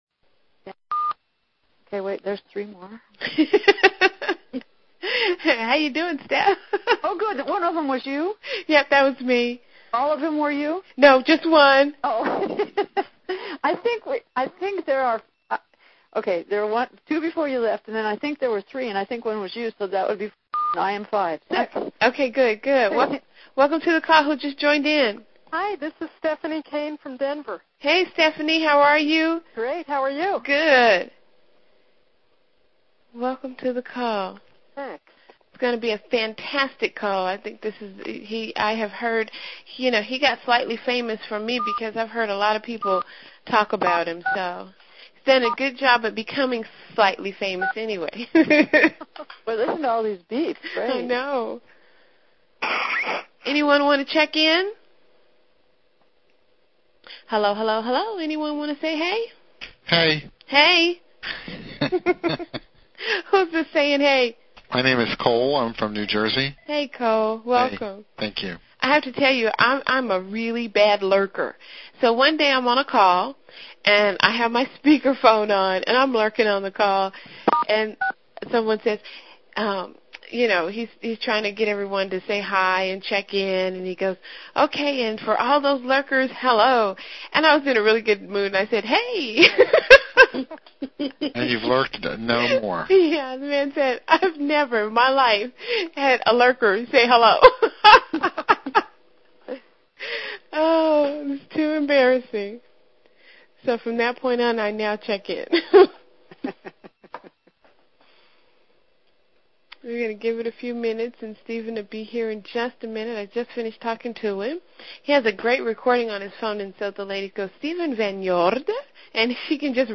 This is a recording of a teleseminar I conducted with the New Coach Connection on Oct. 27, 2005. Here, I discuss how to build visibility and credibility by positioning yourself as a mini-celebrity within a specific target market including: